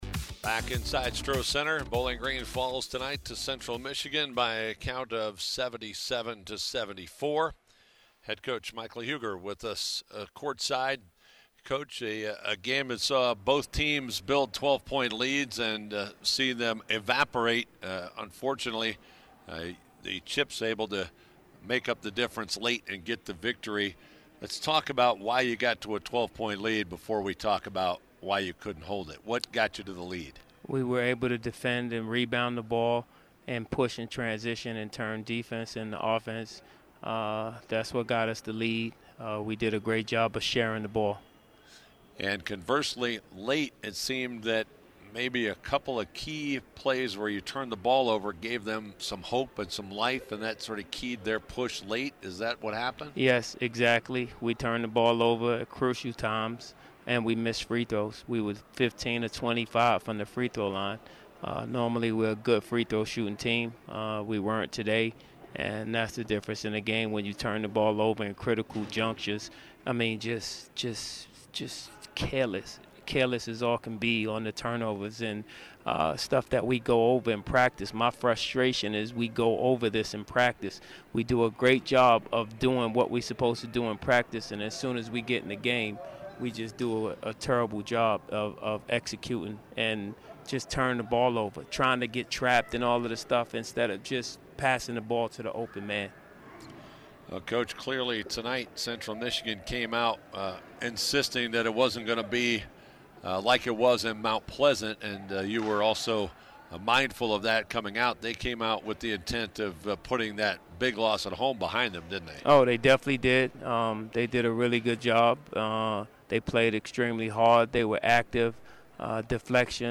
Full postgame interview